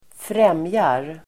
Uttal: [²fr'em:jar]